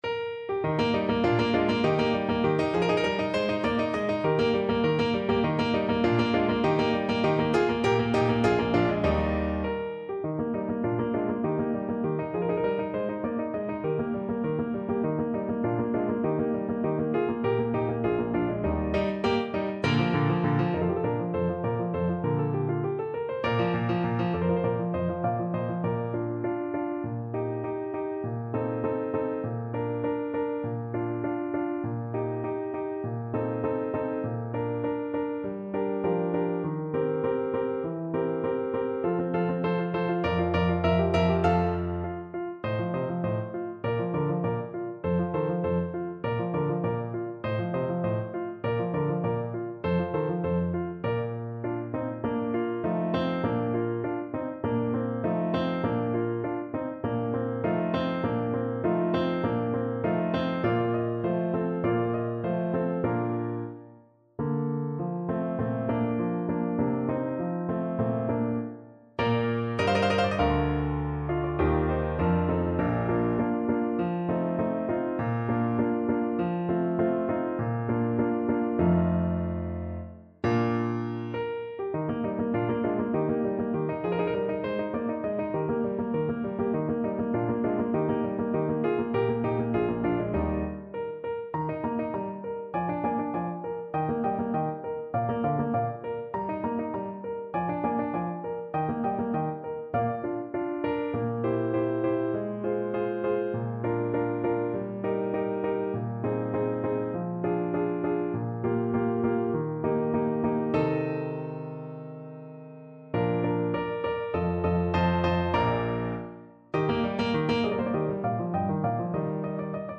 Trombone version
2/4 (View more 2/4 Music)
Allegro (View more music marked Allegro)
Classical (View more Classical Trombone Music)